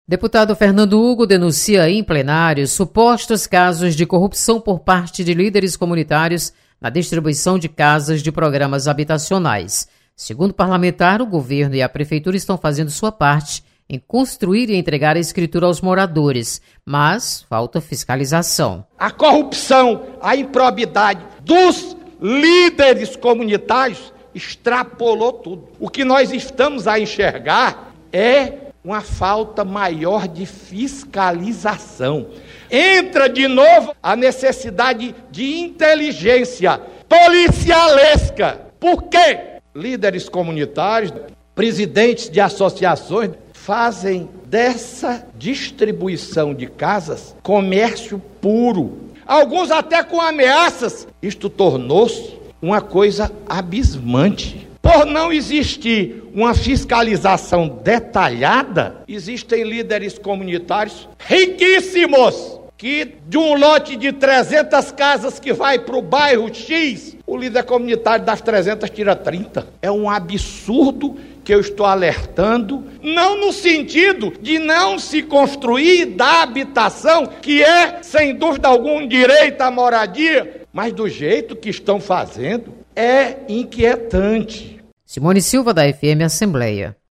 Plenário
Deputado Fernando Hugo denuncia irregularidades na distribuição de casas de programas habitacionais. (1'26").